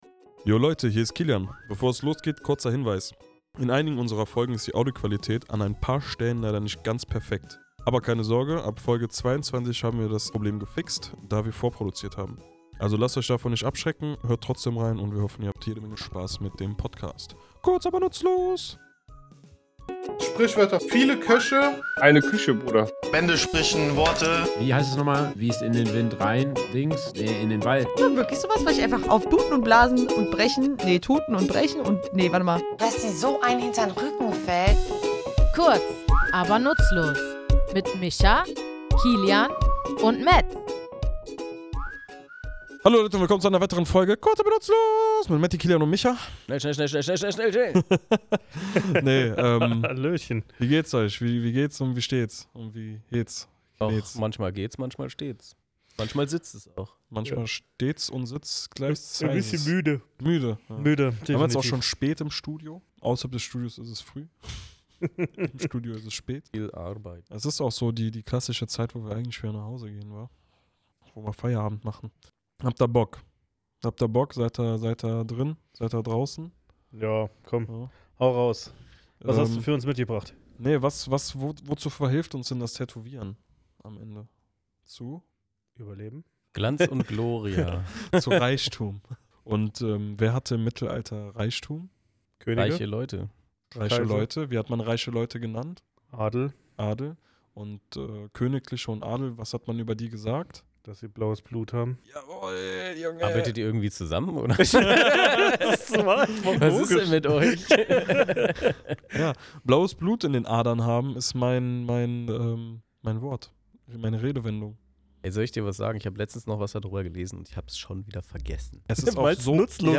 Wir, drei tätowierende Sprachenthusiasten, beleuchten in unserem Tattoostudio die Ursprünge und Hintergründe dieses Ausdrucks.